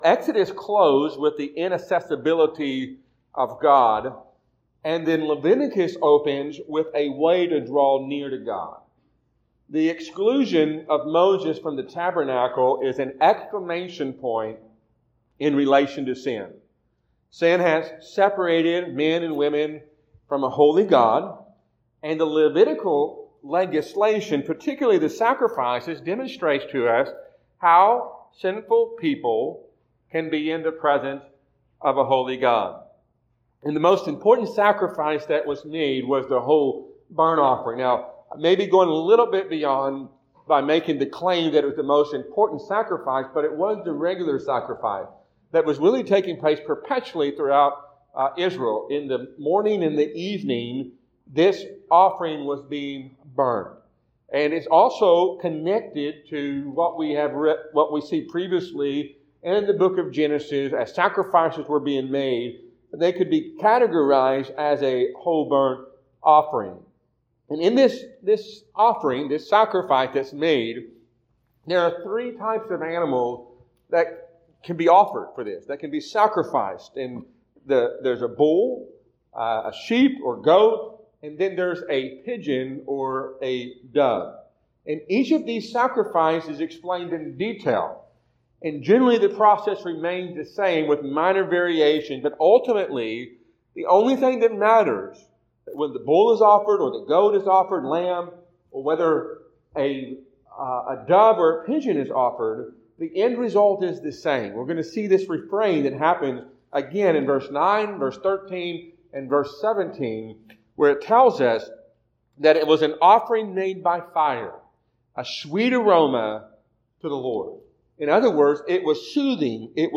A sermon from Leviticus 1:3-17.